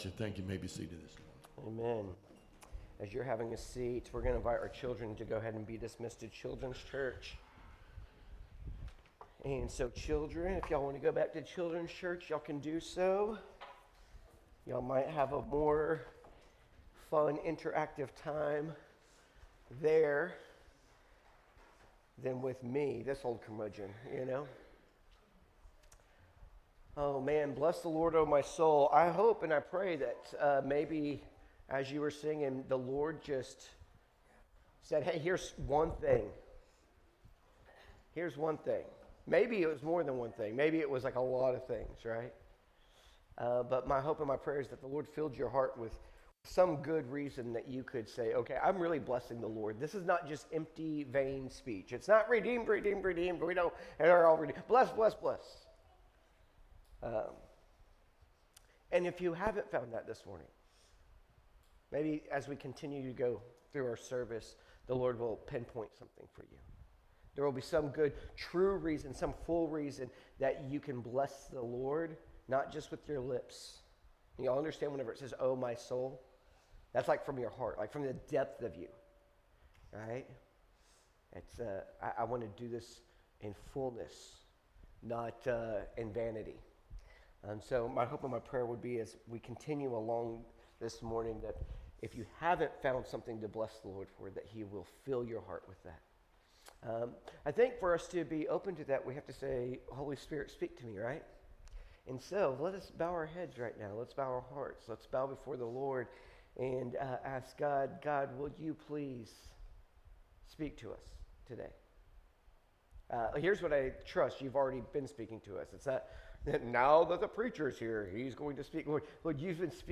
Sunday Service.
Sermons by Friendswood Baptist Church